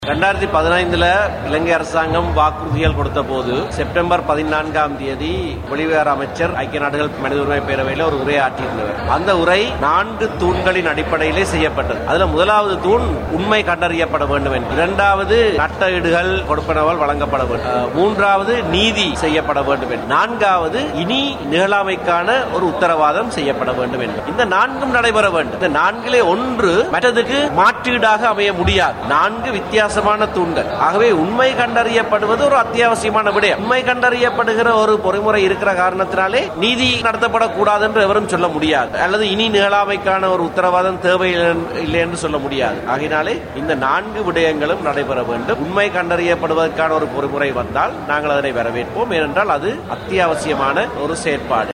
யாழ்ப்பாணத்தில் இடம்பெற்ற நிகழ்வினையடுத்து ஊடகங்களுக்கு கருத்து தெரிவிக்கும் போது, தமிழ் தேசிய கூட்டமைப்பின் ஊடக பேச்சாளர் எம் ஏ சுமந்திரன் இந்த கோரிக்கையை முன்வைத்தார்.